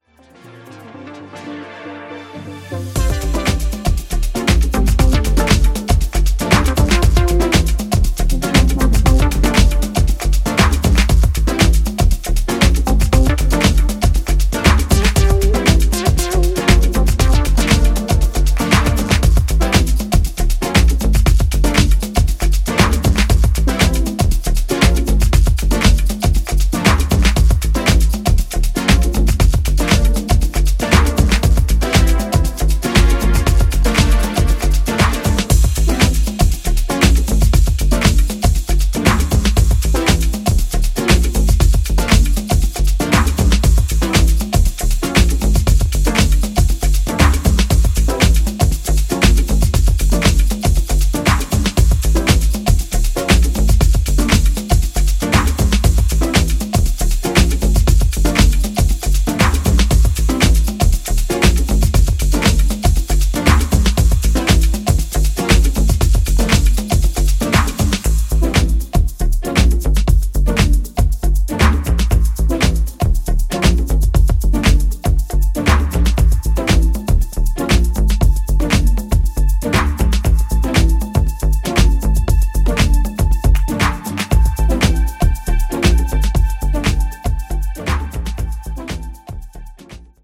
ジャンル(スタイル) DEEP HOUSE / BROKEN BEAT